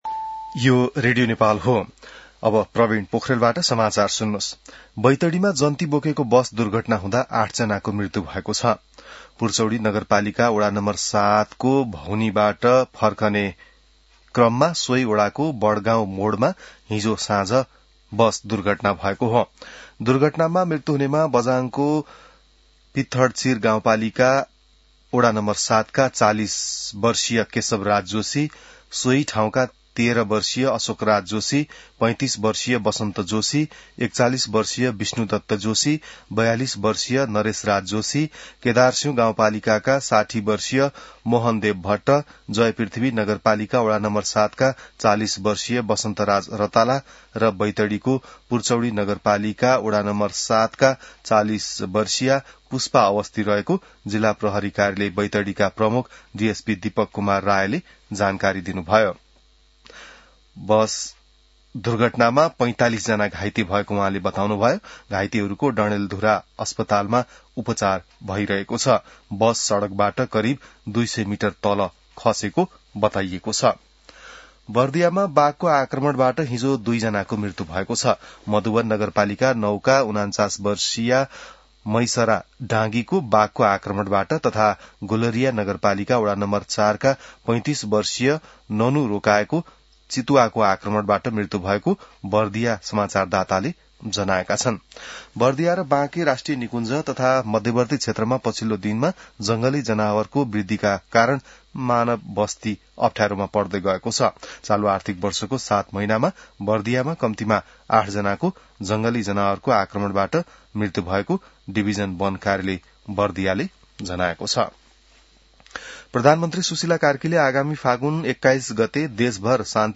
बिहान ६ बजेको नेपाली समाचार : २३ माघ , २०८२